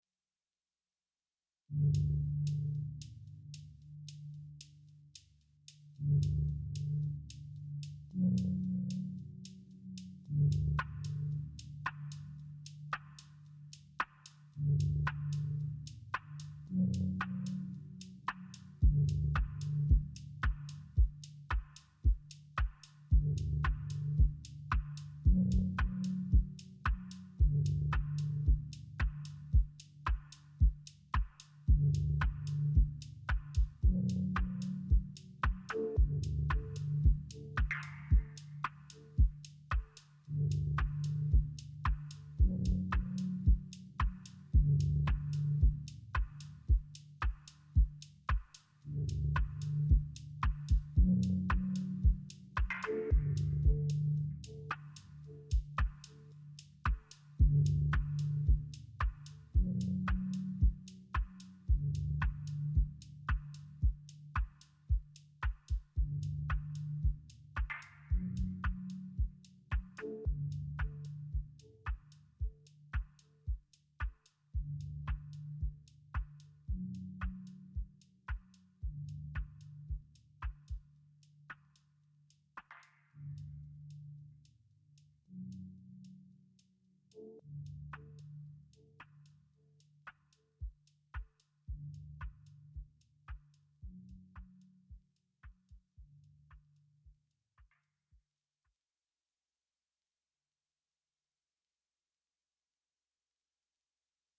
digitakt through ah+fx
been working on getting good levels into overbridge with analog heat, but my recordings have been turning out quiet. any body have any tips on achieving consistent volumes when recording through analog heat? where are your heat levels usually sitting?